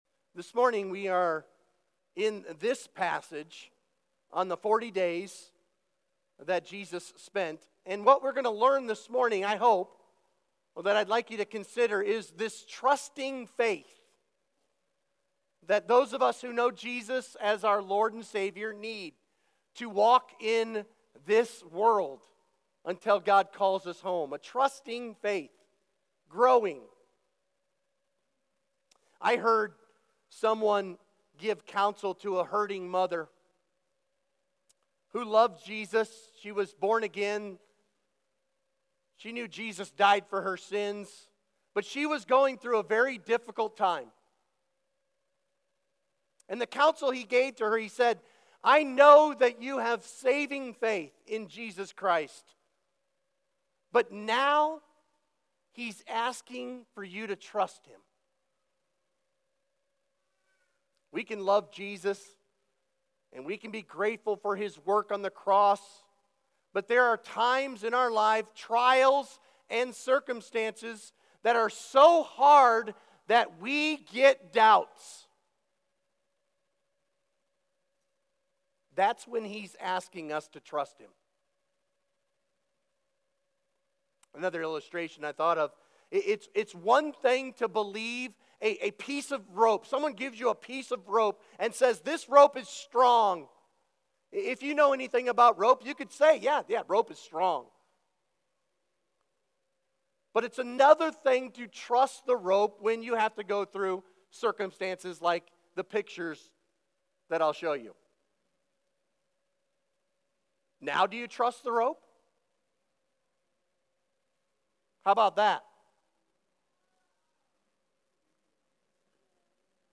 Sermon Questions Read John 20:24-29.